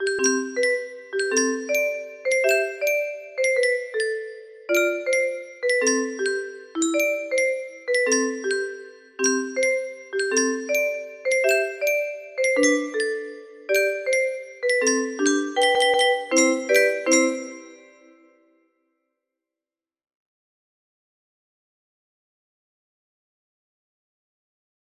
３０２２8 music box melody